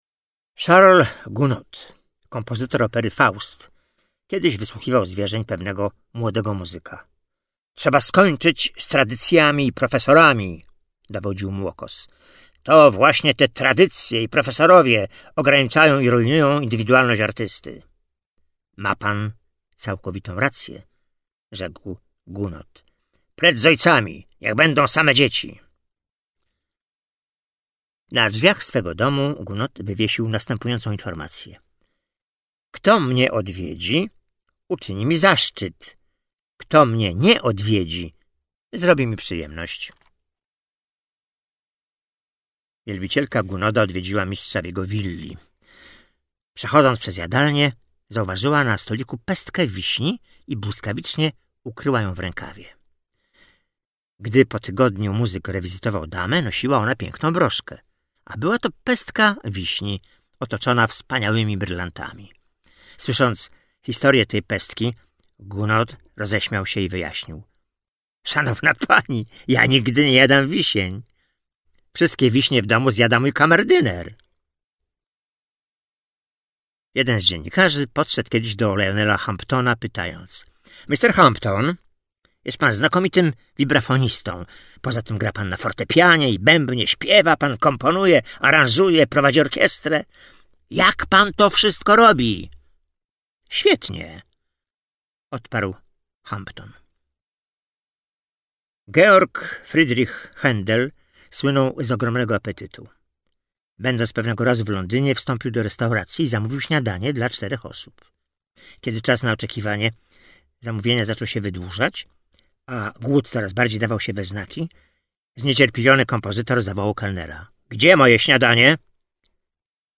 audiobook mp3 do pobrania
Kategoria: humor